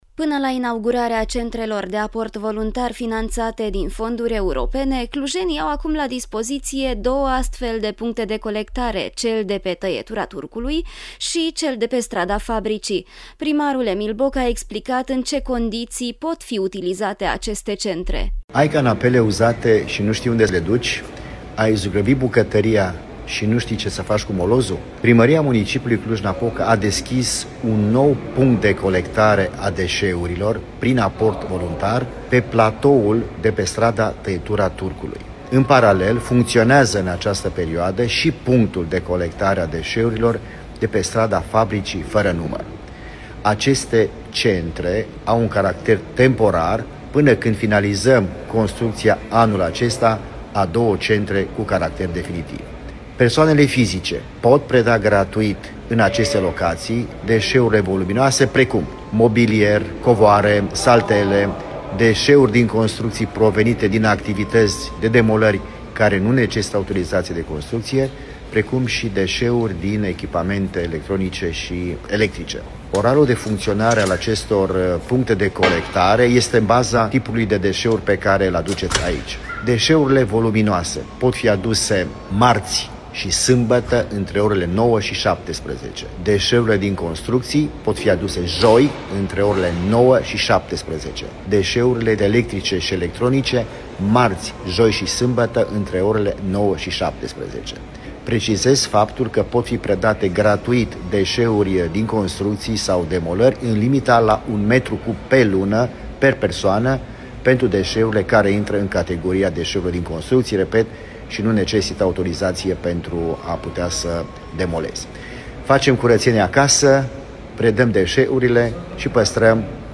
Primarul Emil Boc a explicat în ce condiții pot fi utilizate aceste centre: